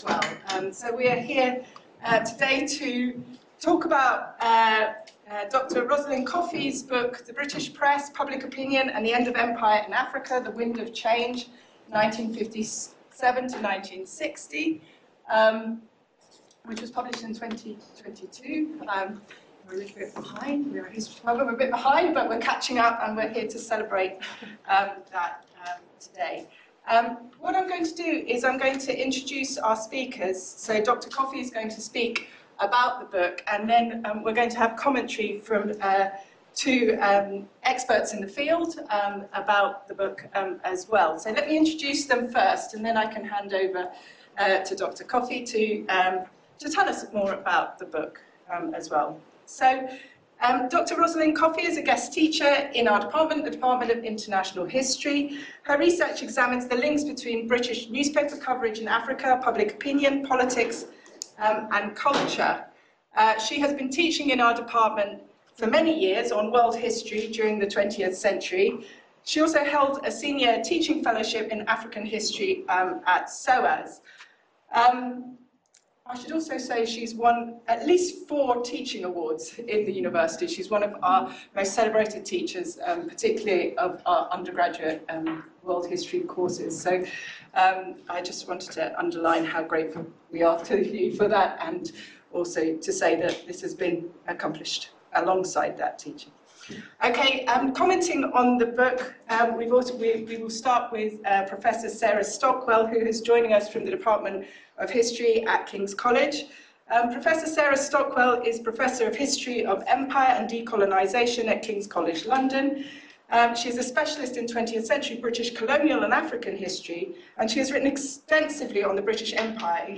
Recordings of some of the events hosted by the Department of International History, LSE since 2009.